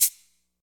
shaker.wav